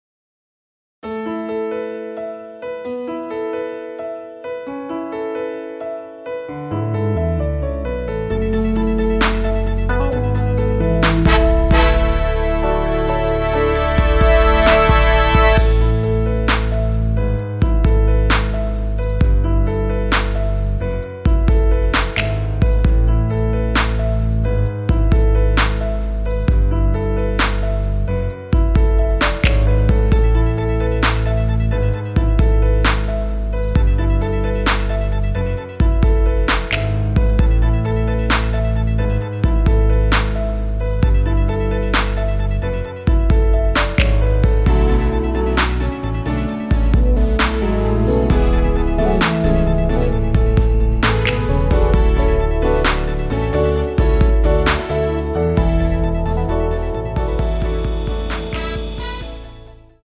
원키에서(-3)내린 MR입니다.
앞부분30초, 뒷부분30초씩 편집해서 올려 드리고 있습니다.